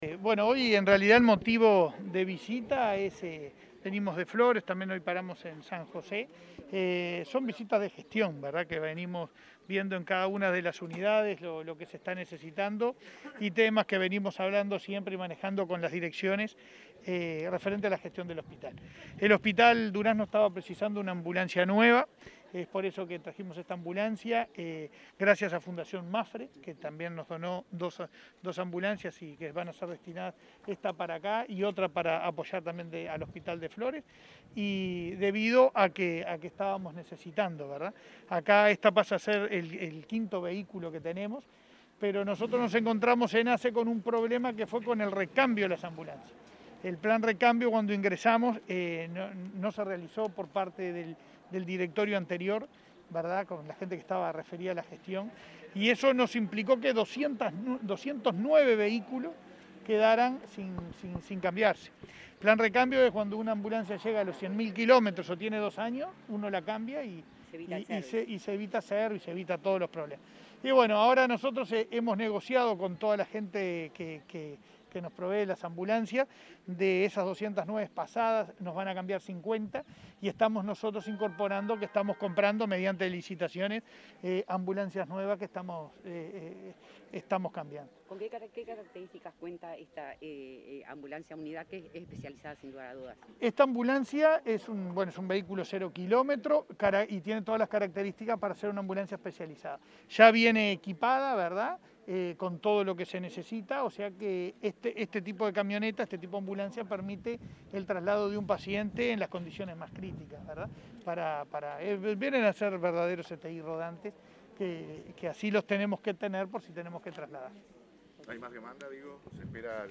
Declaraciones a la prensa del presidente de ASSE, Leonardo Cipriani
Luego dialogó con medios de prensa.